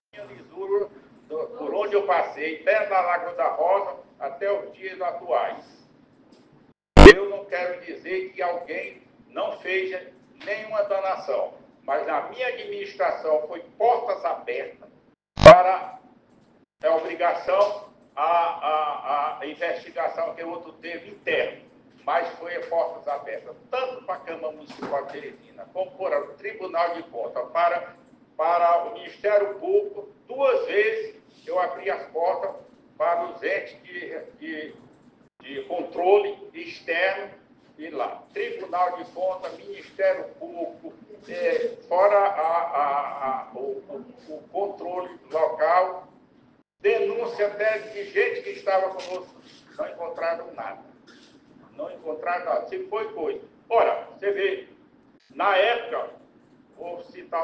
Em entrevista à TV Clube na manhã desta quarta-feira (7), o ex-prefeito Dr. Pessoa (PRD) comentou as declarações do atual prefeito Silvio Mendes (União Brasil) sobre um rombo nos cofres da Prefeitura de Teresina, que chegaria a R$ 3 bilhões.